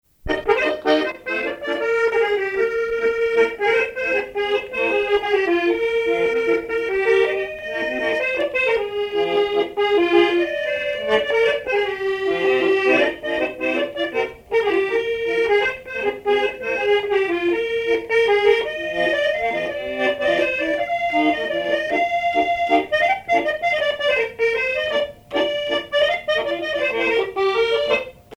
accordéon(s), accordéoniste
danse : marche
Pièce musicale inédite